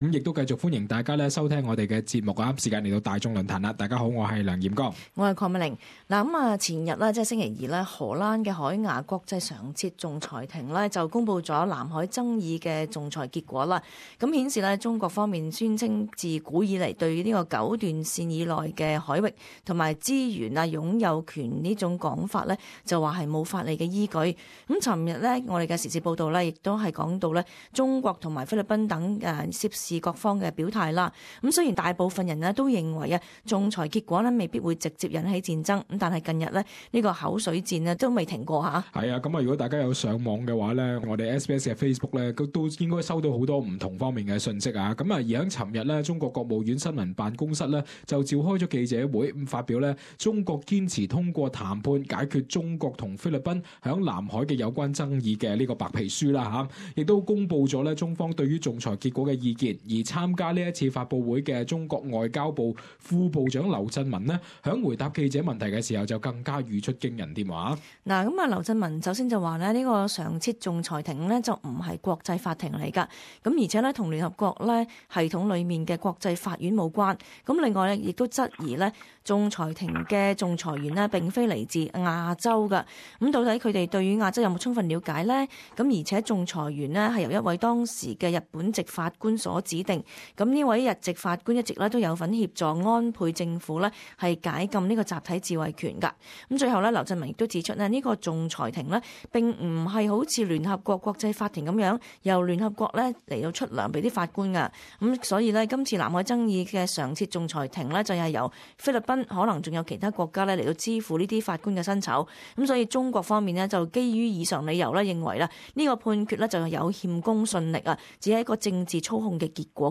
海牙國際法庭就菲律賓提出的南海仲裁案作出書面裁決，稱中國劃設南海九段線抵觸聯合國海洋法公約及沒有法理依據。聽眾在本台節目大眾論壇中發表意見...